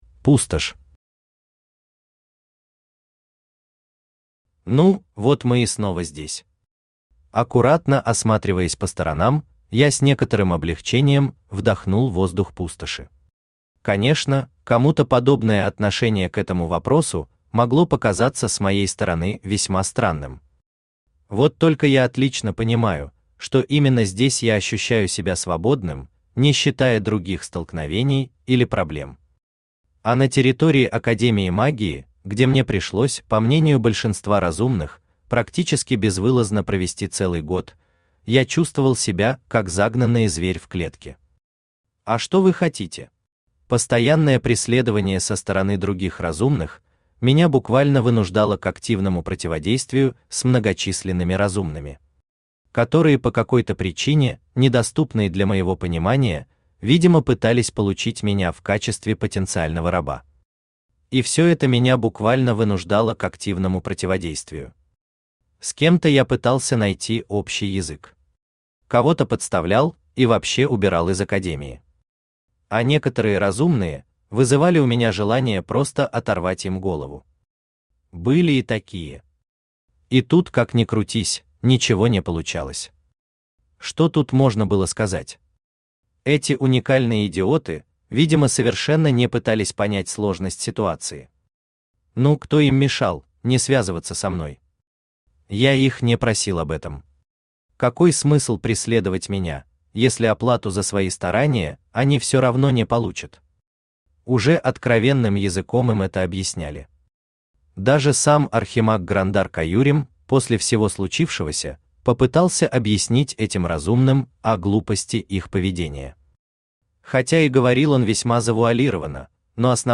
Аудиокнига Лесовик. Лабиринты интриг | Библиотека аудиокниг
Aудиокнига Лесовик. Лабиринты интриг Автор Хайдарали Усманов Читает аудиокнигу Авточтец ЛитРес.